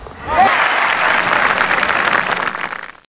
1 channel
APPLAUSE.mp3